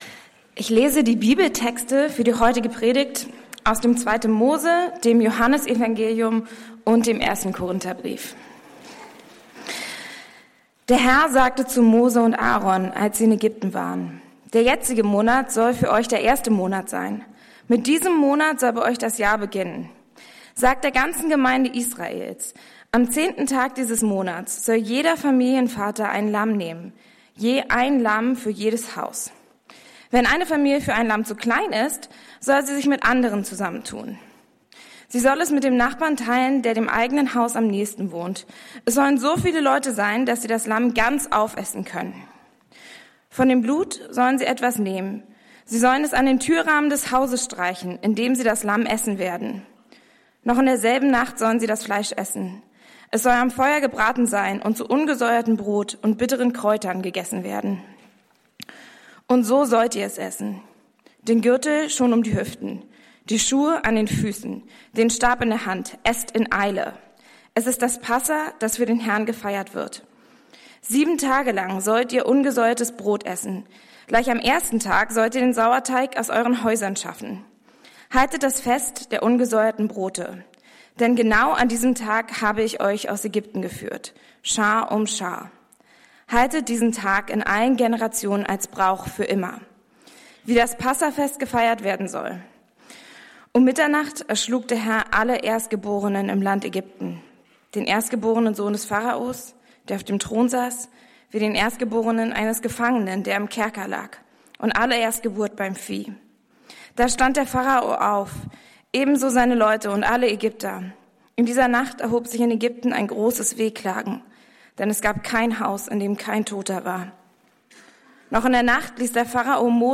Der Aufbruch: Gottes kompromissloser Ruf in die Freiheit ~ Berlinprojekt Predigten Podcast